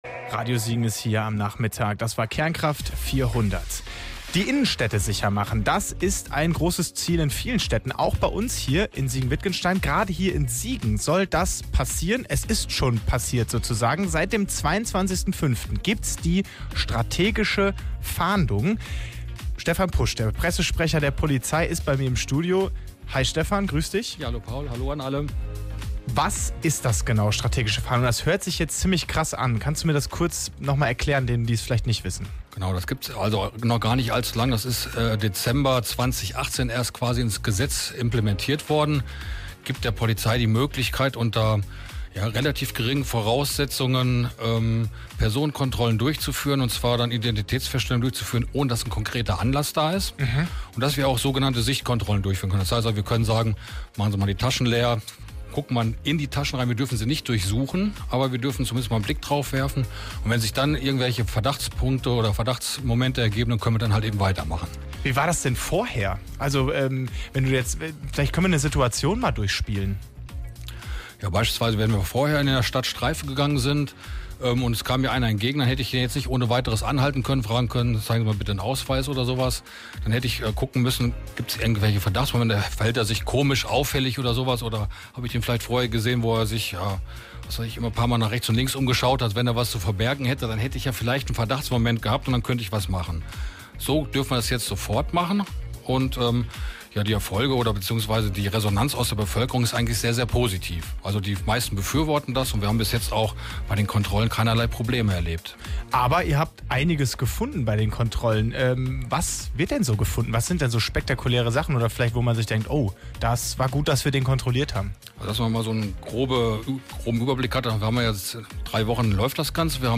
Strategische Fahndung endet - Interview mit Polizei - Radio Siegen